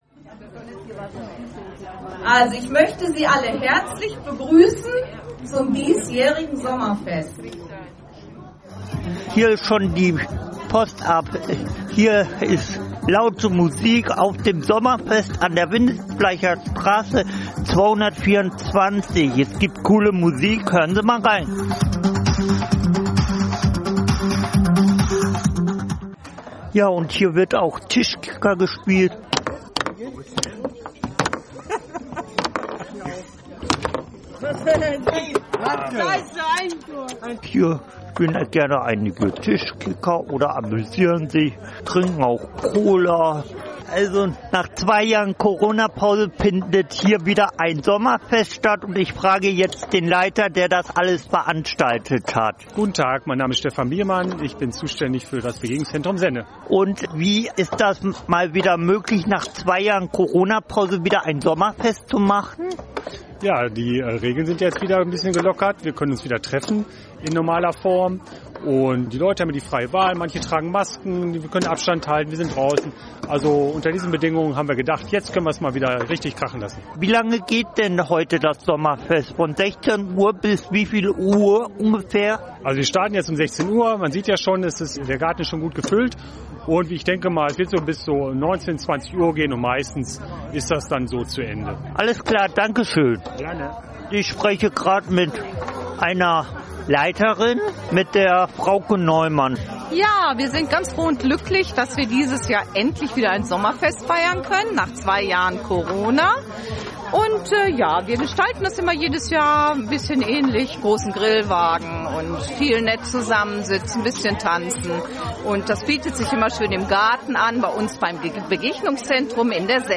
Sommerfest im Begegnungszentrum Senne
Sommerfest-BGZ-Senne.mp3